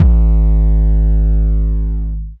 DDK1 808 7.wav